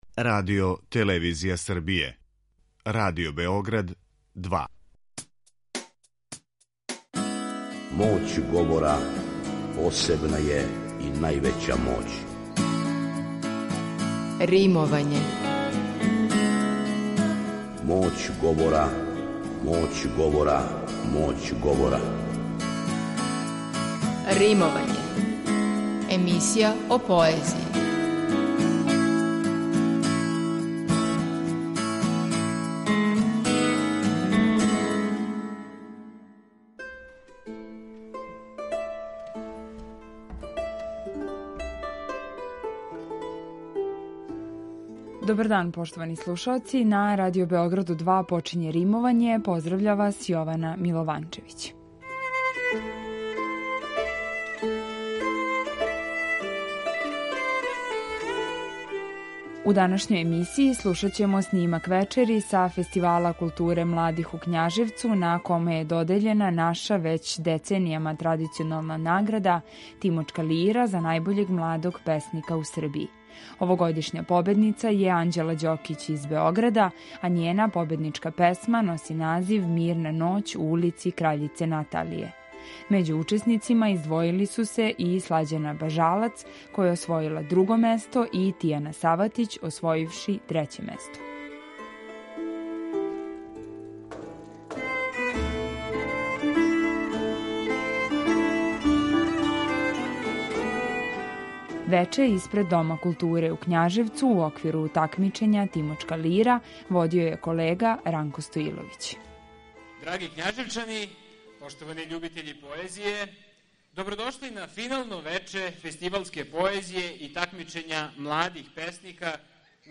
Снимак вечери са Фестивала културе младих у Kњажевцу